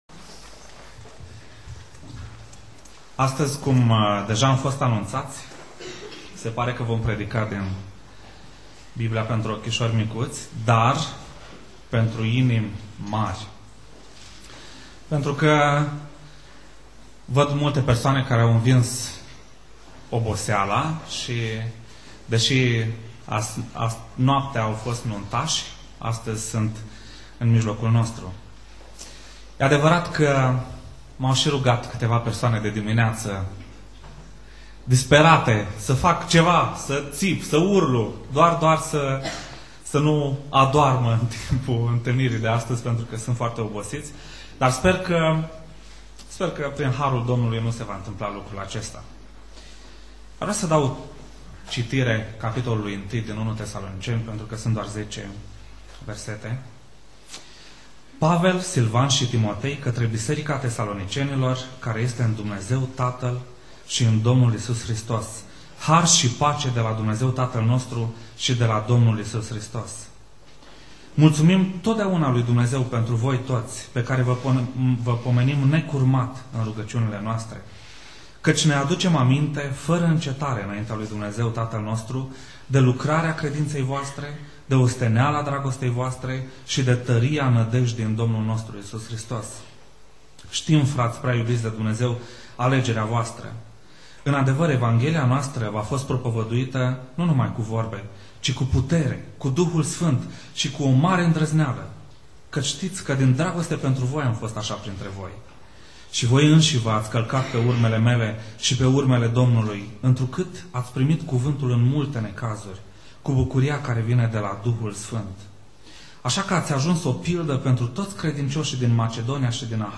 Predica Exegeza - 1 Tesaloniceni Cap.1